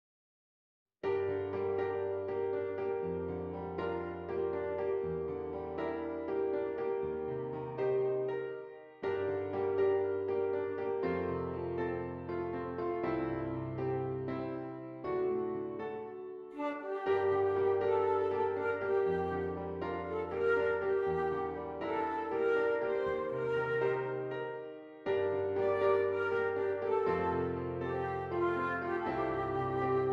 Flute Solo with Piano Accompaniment
Does Not Contain Lyrics
G Minor
Moderately